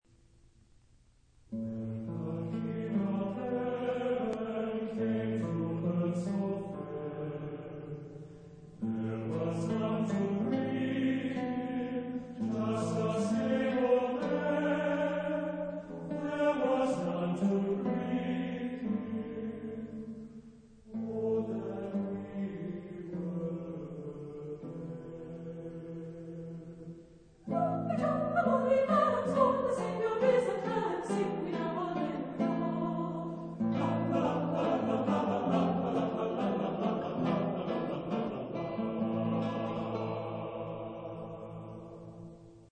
Type de choeur : SATB  (4 voix mixtes )
Instruments : Guitare (1)